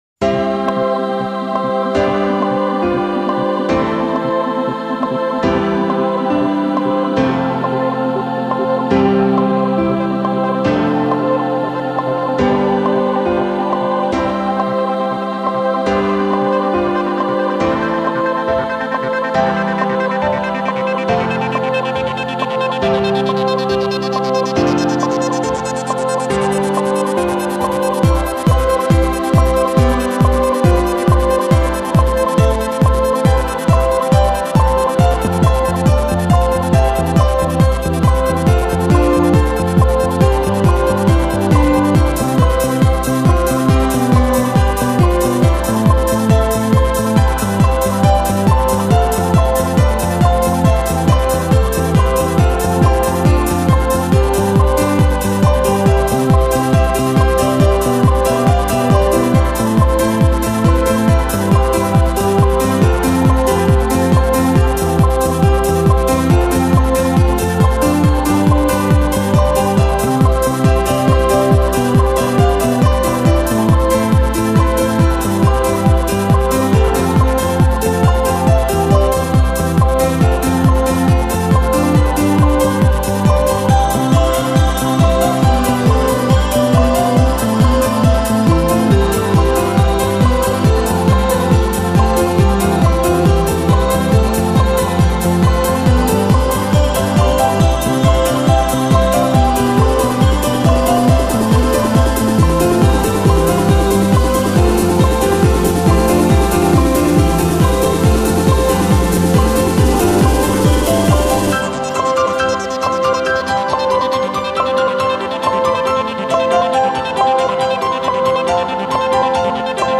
테크노 버전입니당
캐논-테크노.mp3